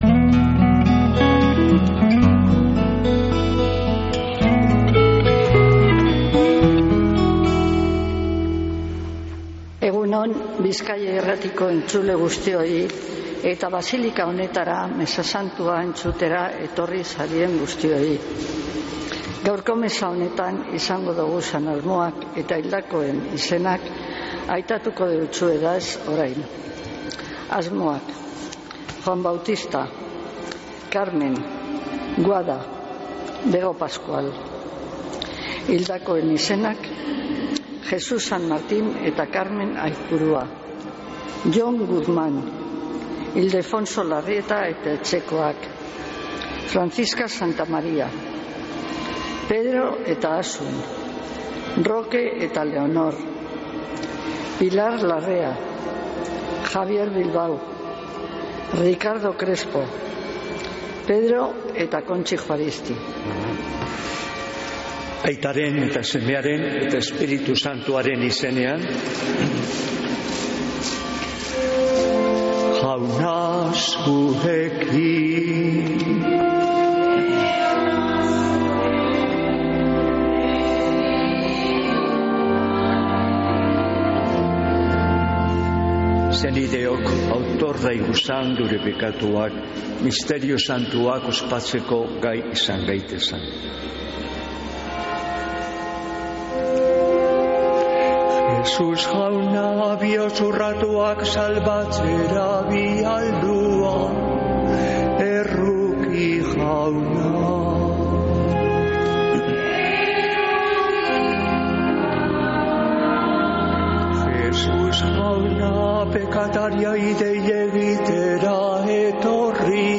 Mezea (25-09-04) | Bizkaia Irratia